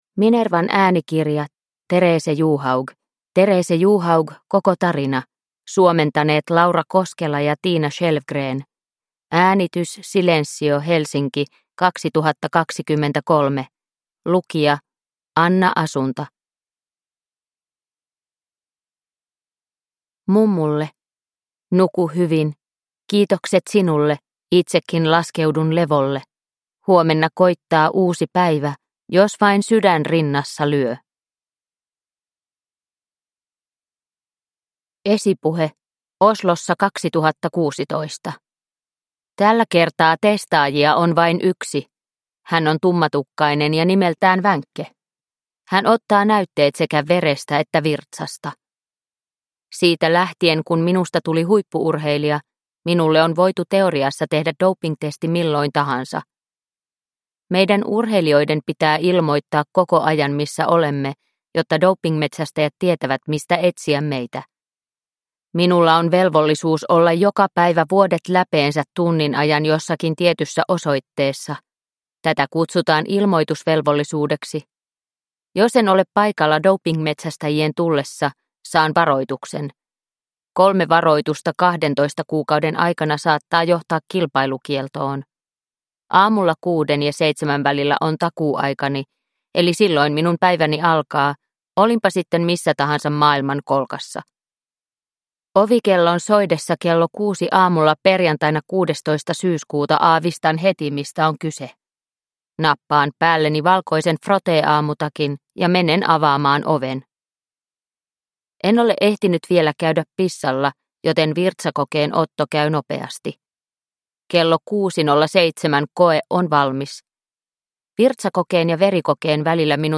Therese Johaug – Ljudbok – Laddas ner